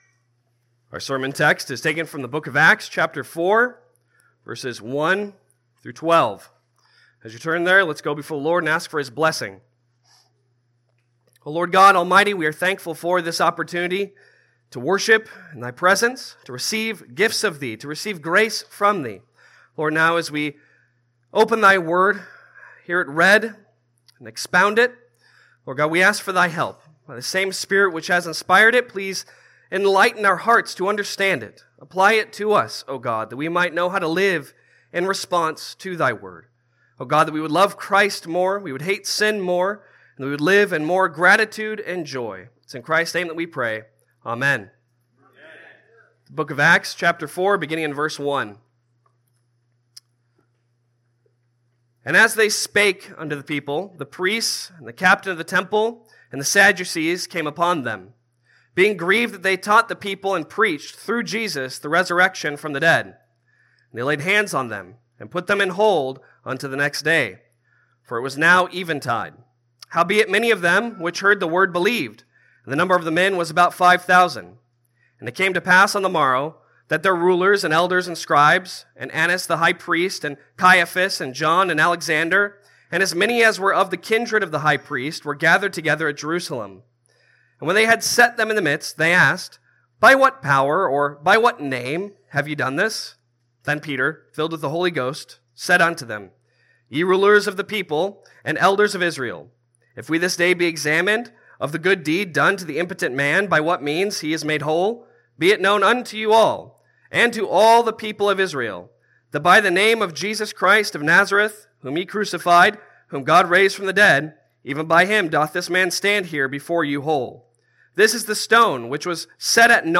5 Solas Passage: Acts 4:1-12 Service Type: Sunday Sermon Download Files Bulletin « Sola Fide